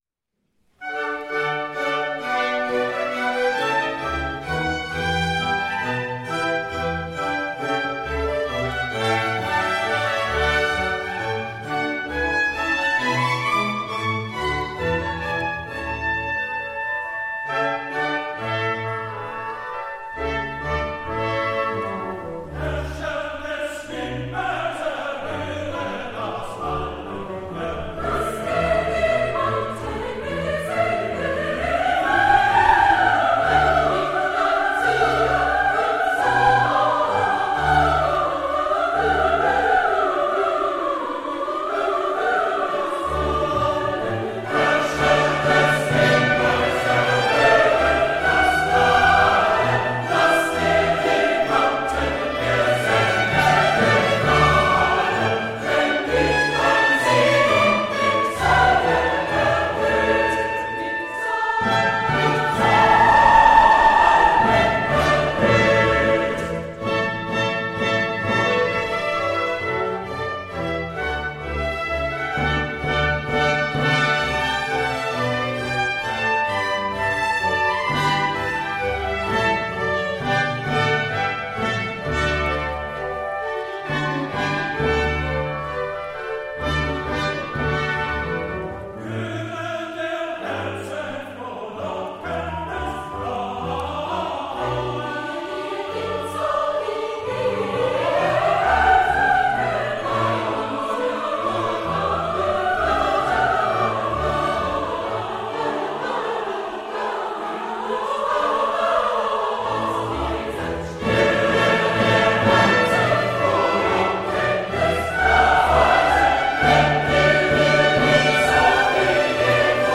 Kichenmusik / Chorprojekte
Weihnachtsoratorium von J. S. Bach des Oratorienchors Mariae Himmelfahrt, Weilheim
"Weihnachtsoratorium" Mitschnitt live